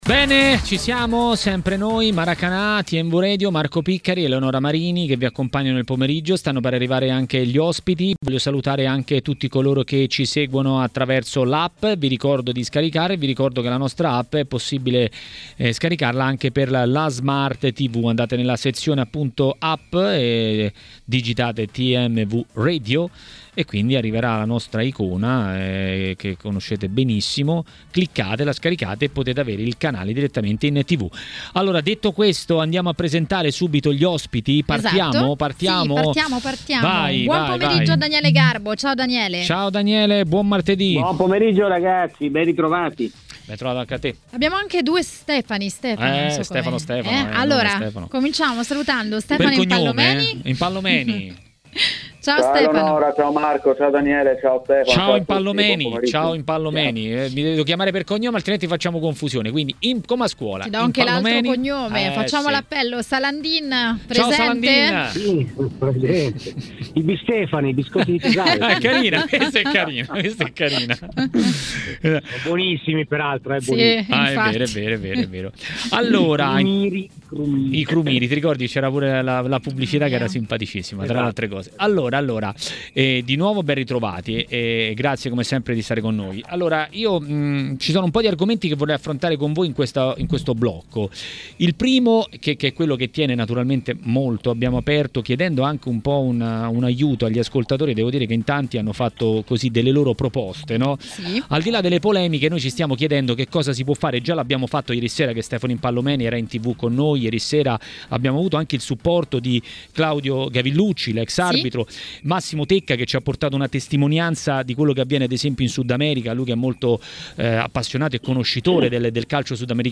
A Maracanà, trasmissione di TMW Radio, è intervenuto il giornalista ed ex calciatore Stefano Impallomeni per parlare dei temi del giorno.